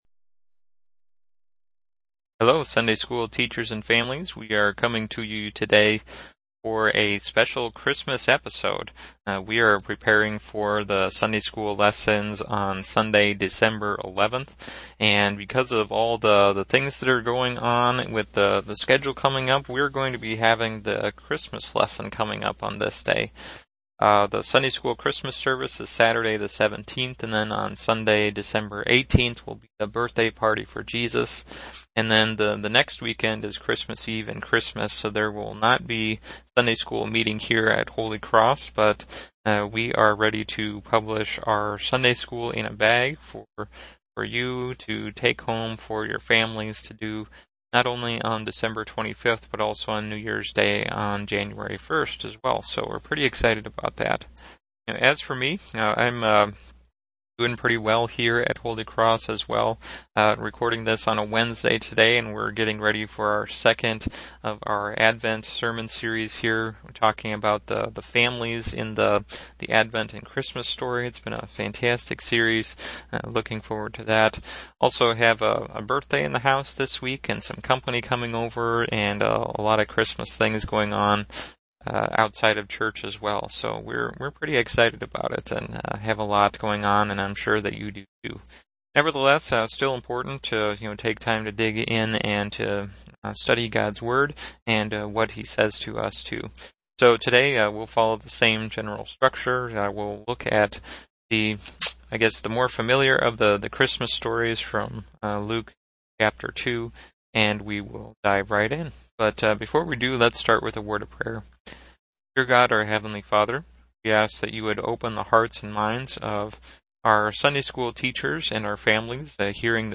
Our “Special Guest” this session is once again the dramatized ESV version of Luke 2. Listen in for some fun facts and trivia about the real story of Christmas!